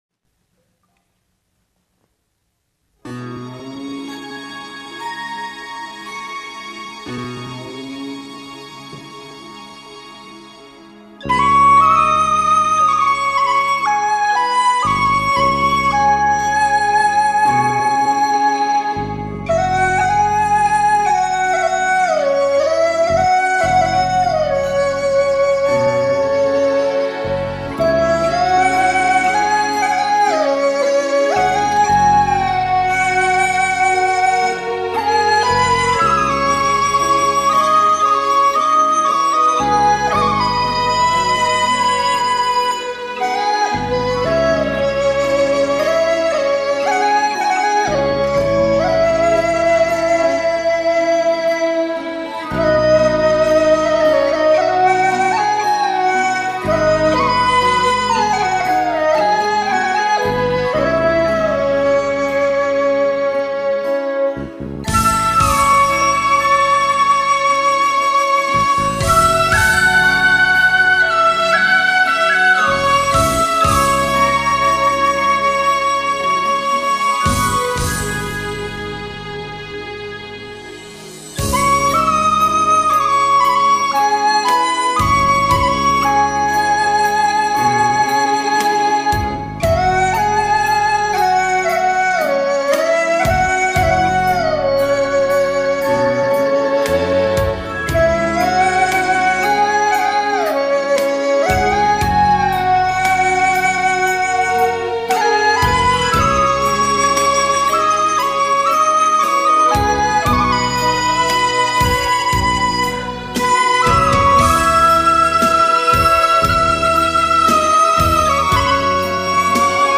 民乐
竹笛
乐器：笛子